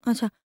TALK 3.wav